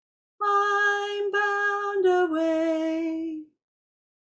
It has a bright sound and finishes on do.
Now let’s sing the last phrase: